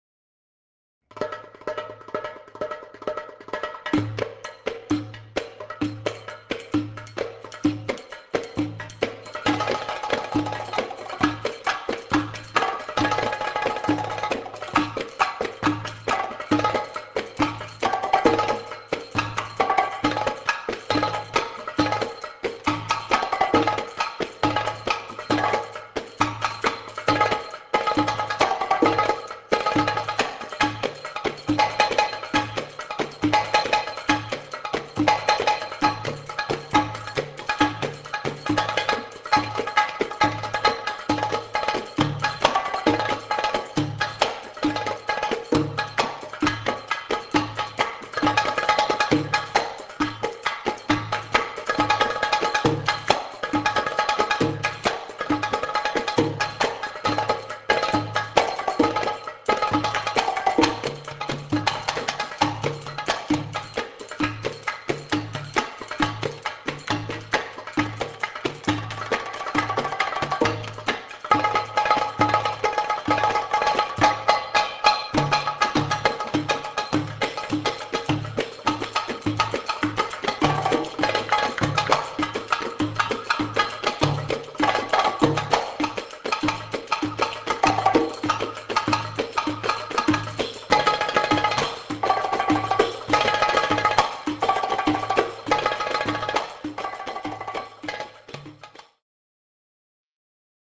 Percussion
Tabla